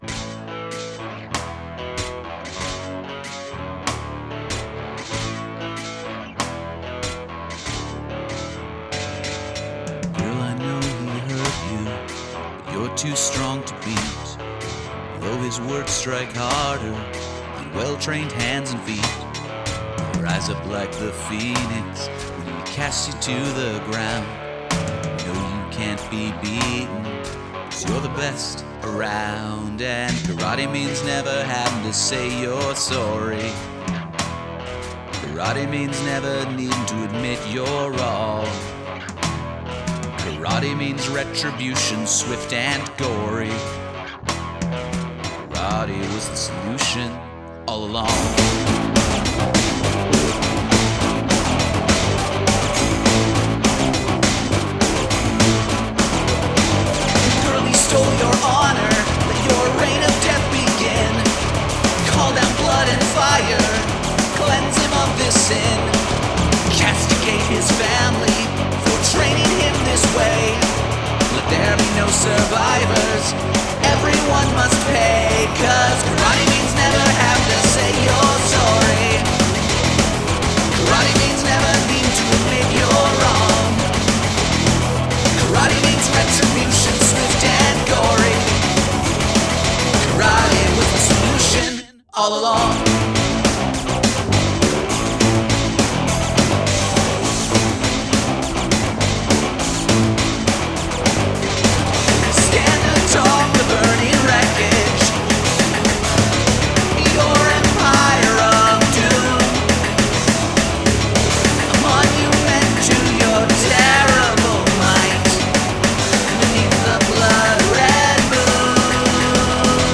Interesting choice of percussion sounds. The groove itself is pretty good but some parts sound like creaky bed springs.
Vocals could come up in the opening and throughout, getting stepped on by the guitars.
Snare is waaaay loud in fast parts of the song.